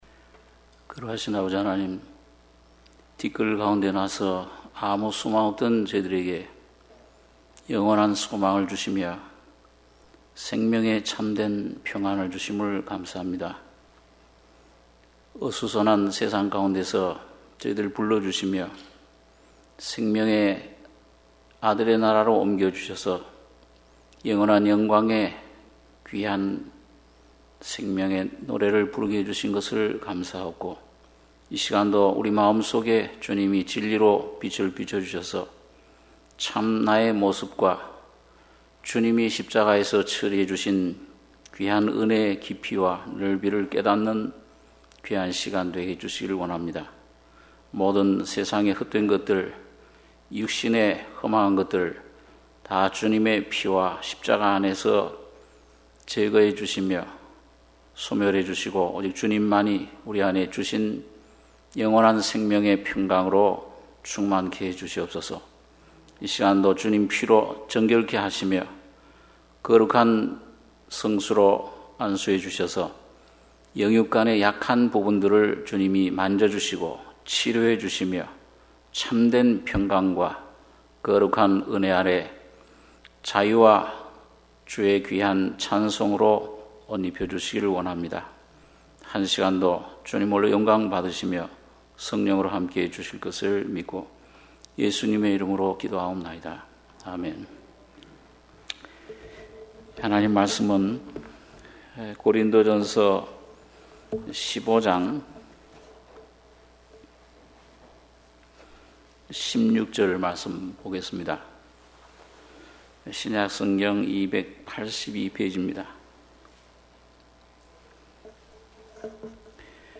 수요예배 - 고린도전서 15장 16절 ~ 26절